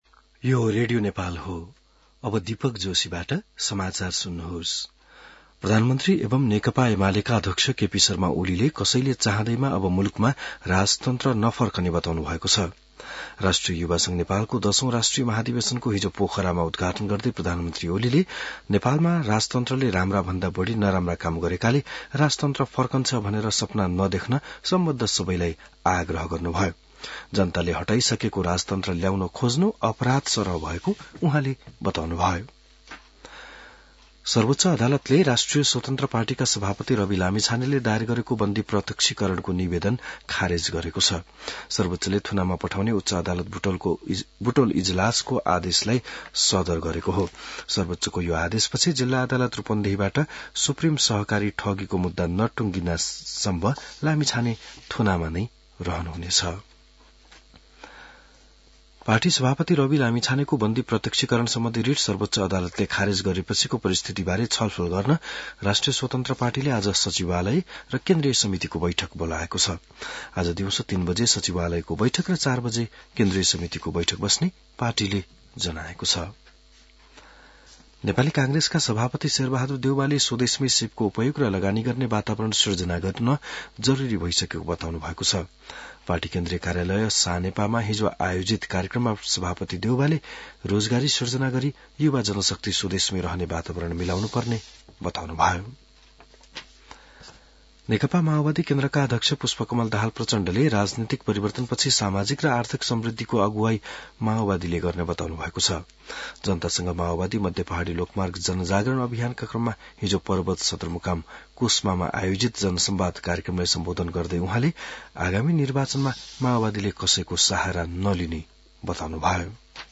बिहान १० बजेको नेपाली समाचार : १० जेठ , २०८२